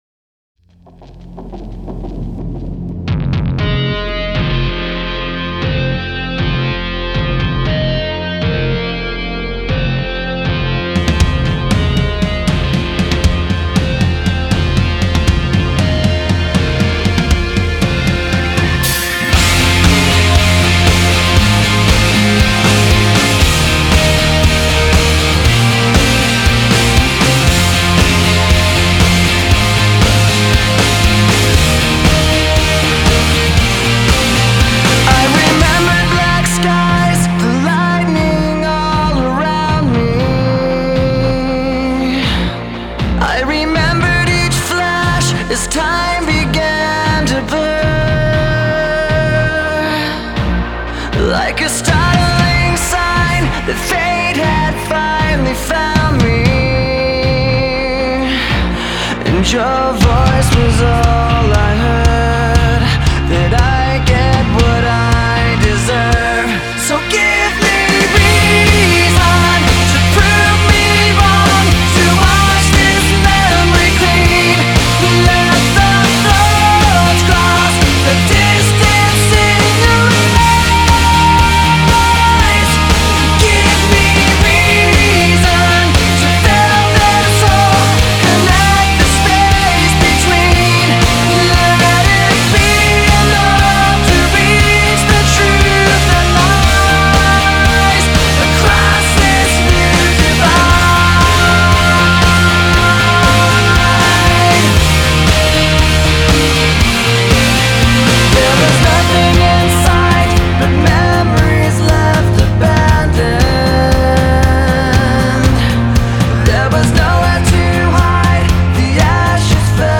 • Жанр: Alternative, Rock